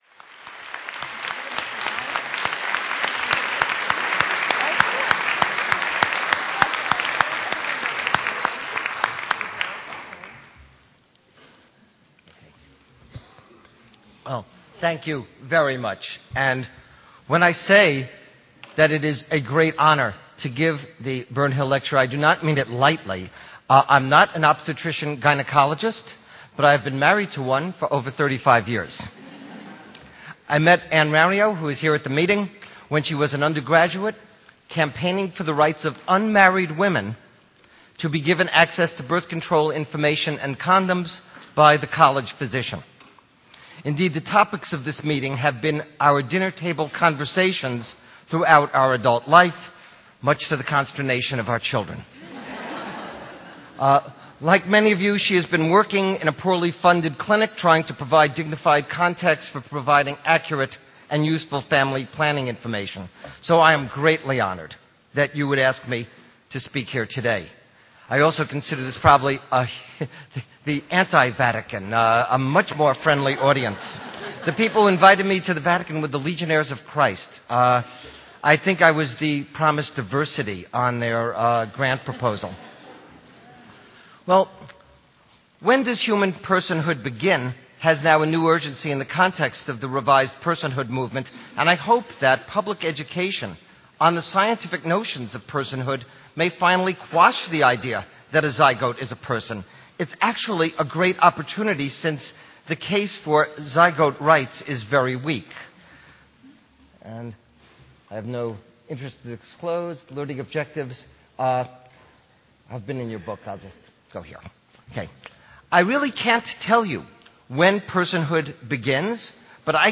When does personhood begin? In this 20' online lecture